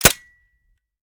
weap_decho_fire_last_plr_mech_01.ogg